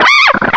cry_not_simisear.aif